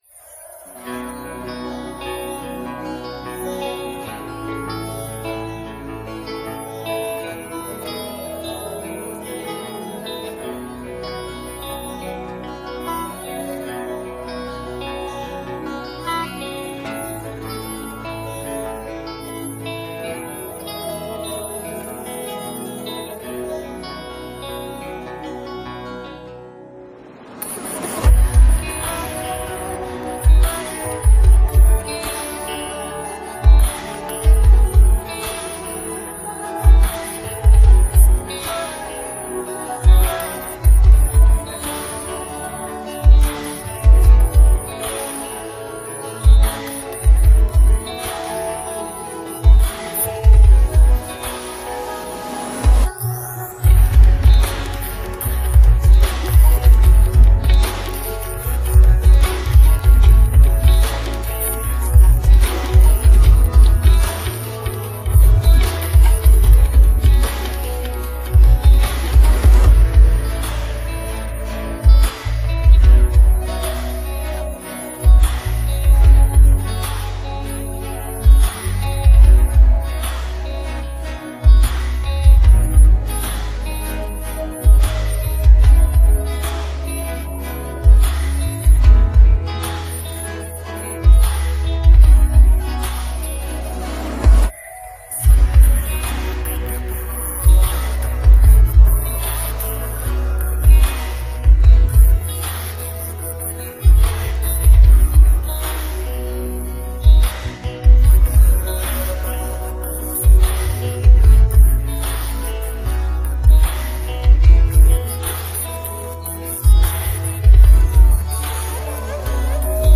Female Vocal